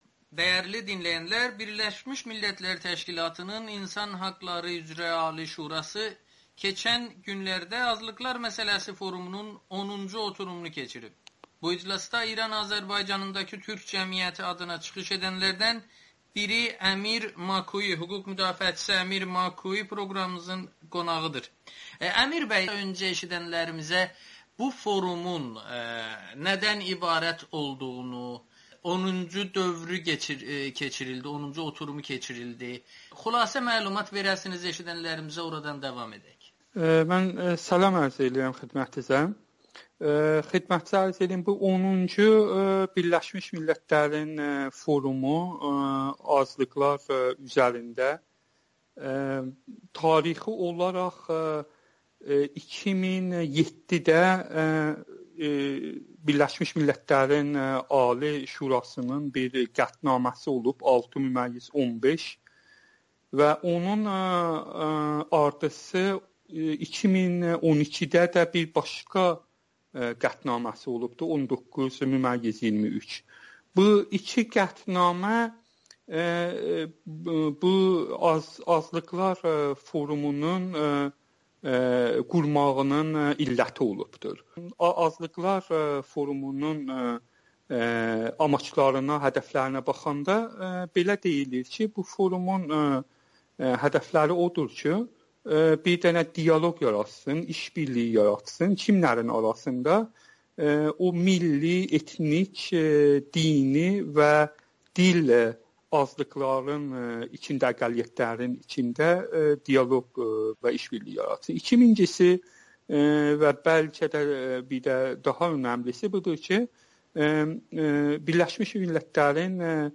müsahibədə bu forumun funksiyası və önəmi haqqında danışıb.